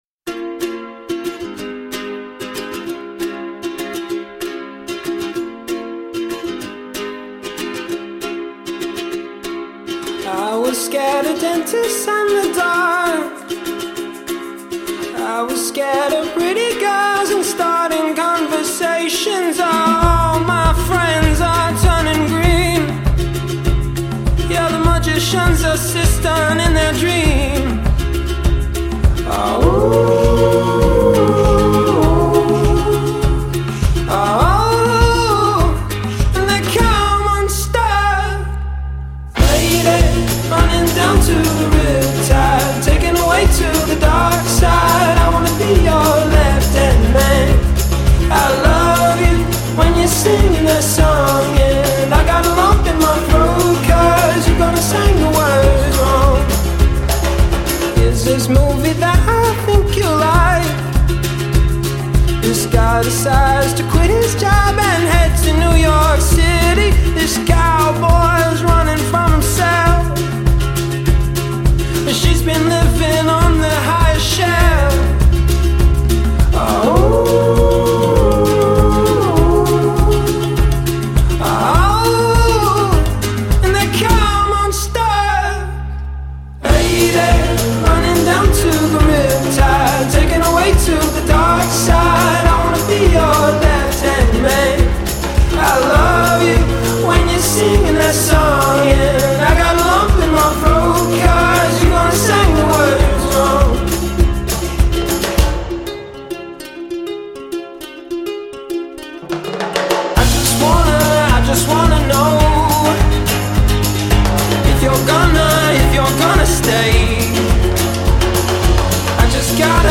leichtfüßige